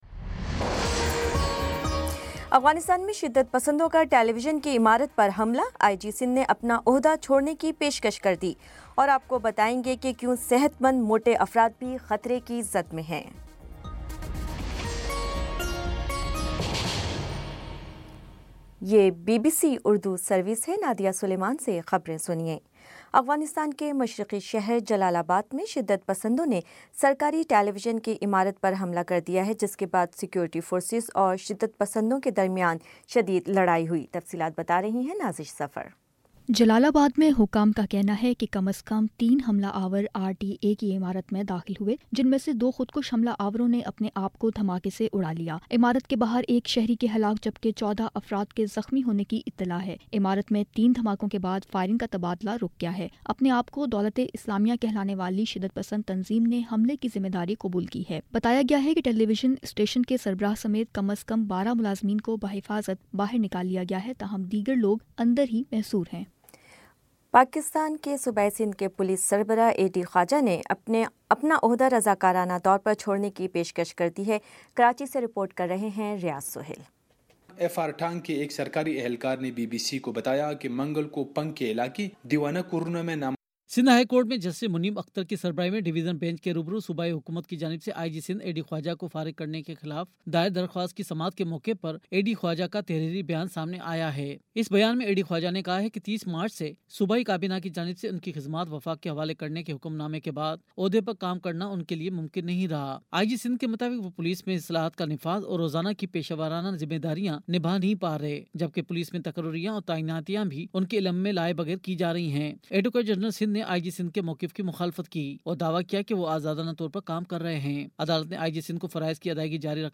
مئی 17 : شام پانچ بجے کا نیوز بُلیٹن